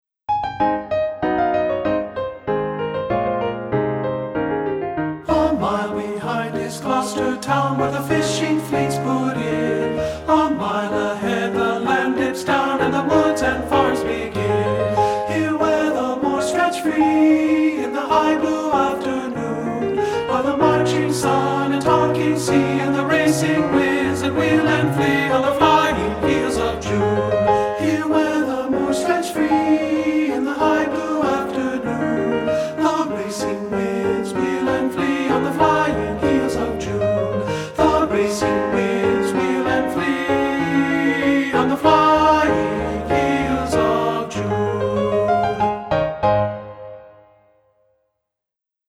Unison/Optional Two-Part Unchanged Voices with Piano
• Piano
Ensemble: Tenor-Bass Chorus
Accompanied: Accompanied Chorus